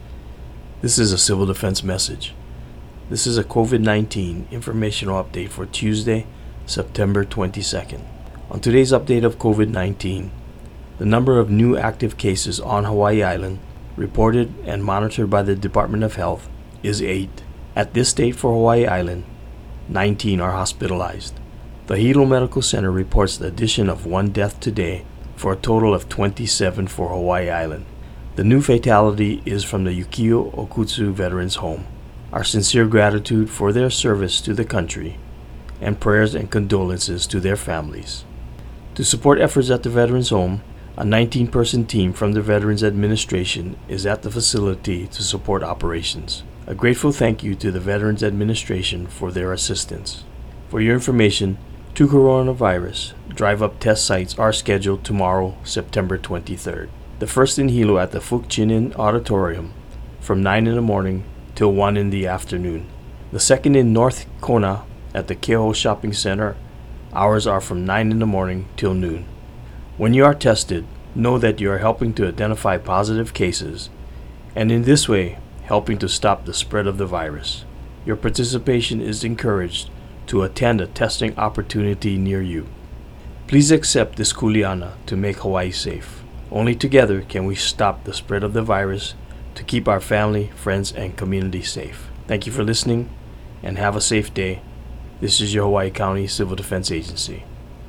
(BIVN) – The Hawaiʻi County Civil Defense issued a radio update on Tuesday morning, reporting the number of new cases, current hospitalizations, and total deaths with COVID-19.